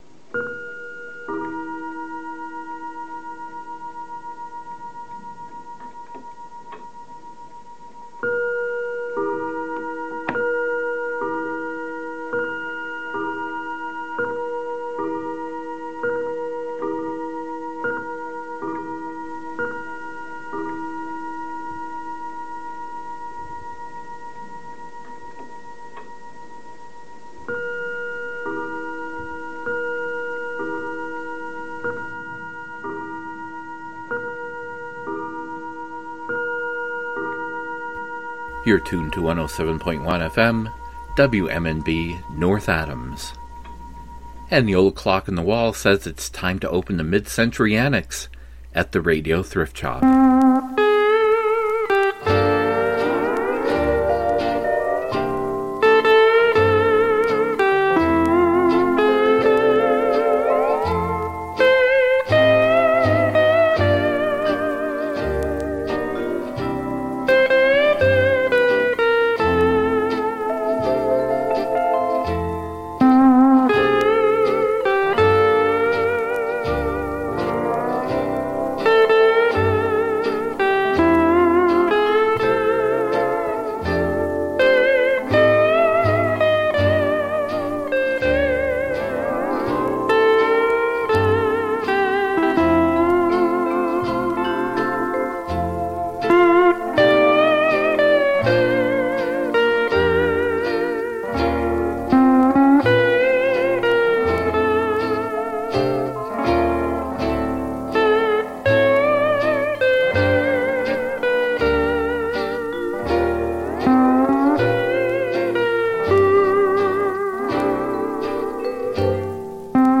Program Type: Music Speakers